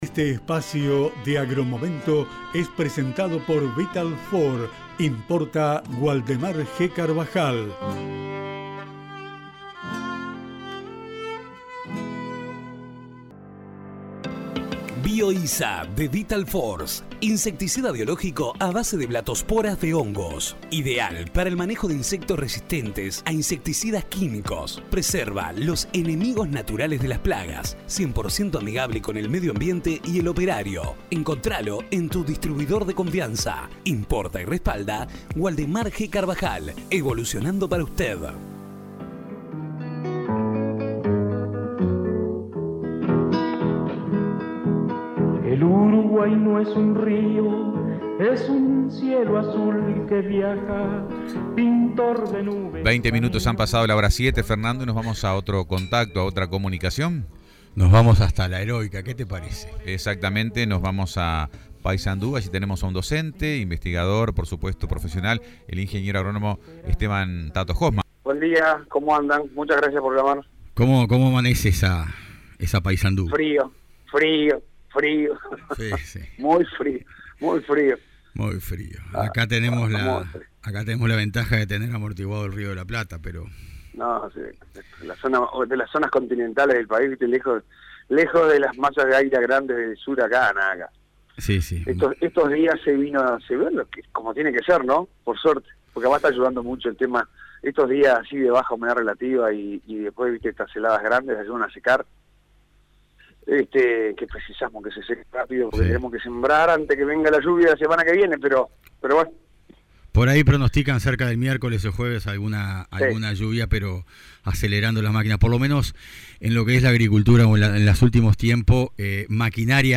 Entrevista en el programa Agromomento de Nueva Helvecia